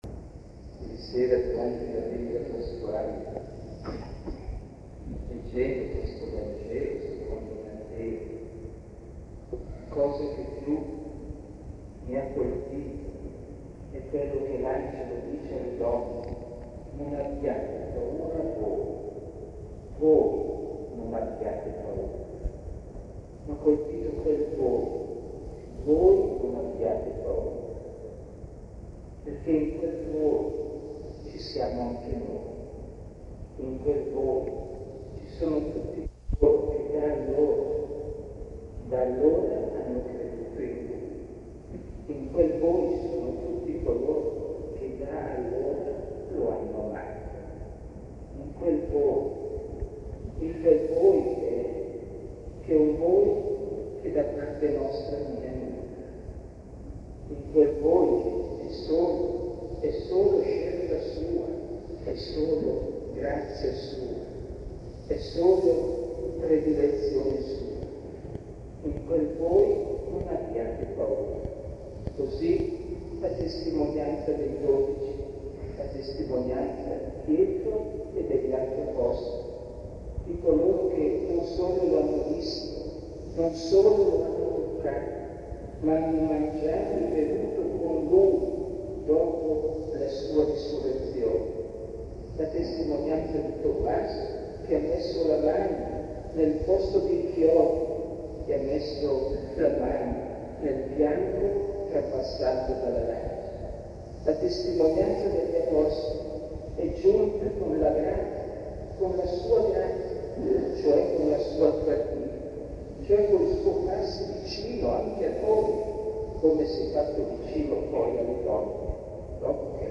OMELIA